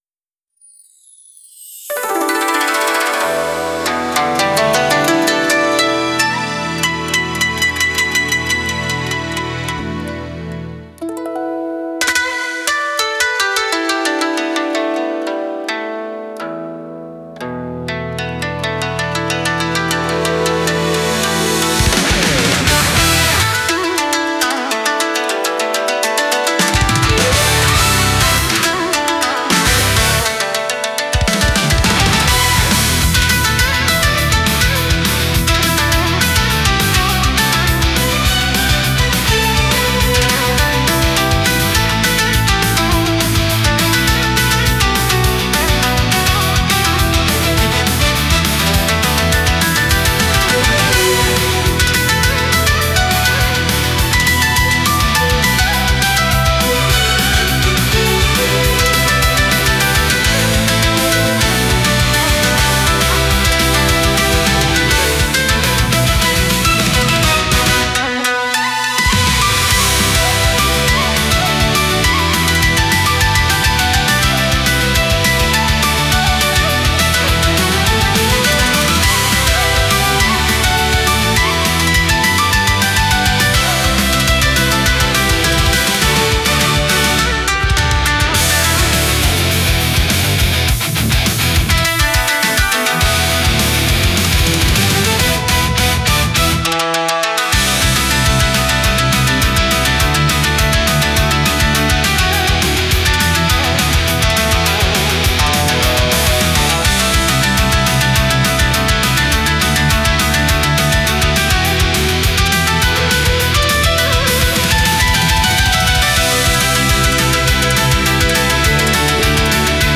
BGM / Instrumental